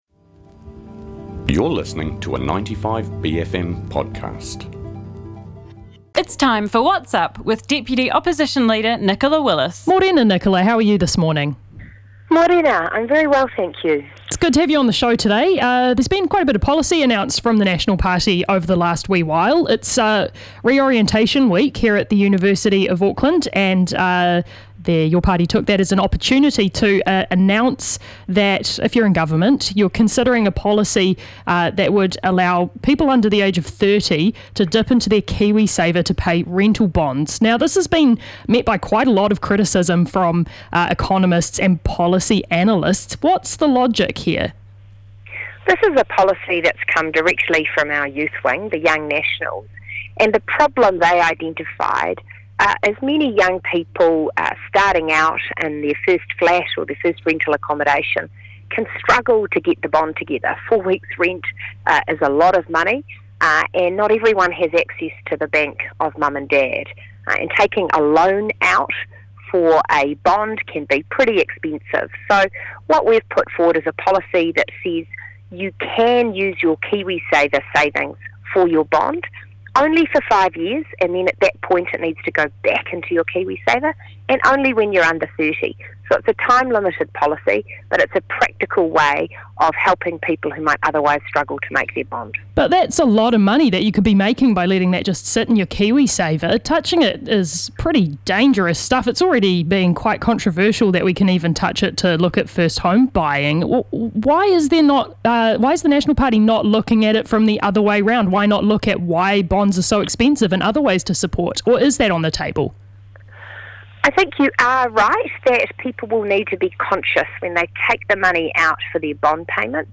Our weekly catch up with the Deputy Leader of the Opposition, Nicola Willis.